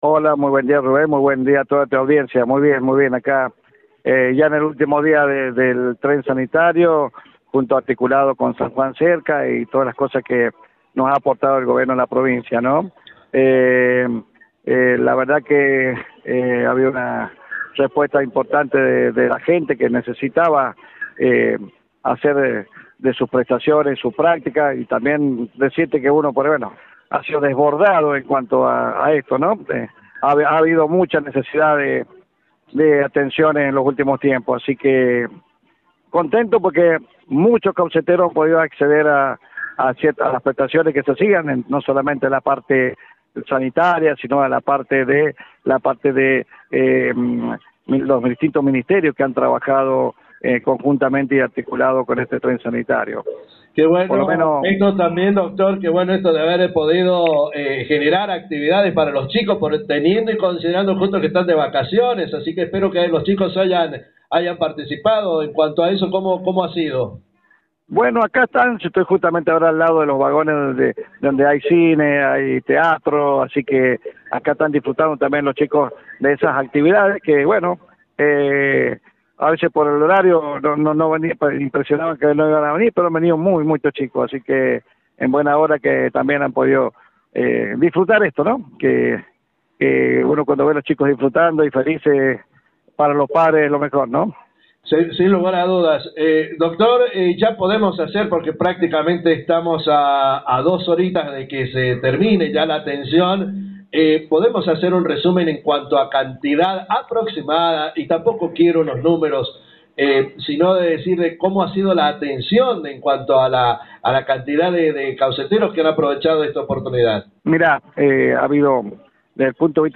Por otro lado en la charla con radio Genesis, abordó detalles de la atencion de los trailer sanitarios en Caucete, y la remodelacion y construccion de nuevos CAPS en el departamento, por ultimo abordó detalles sobre el estado sanitario de los cauceteros ante la temporada invernal,en cuanto a casos de enfermedades respiratorias y la necesidad de estar vacunados.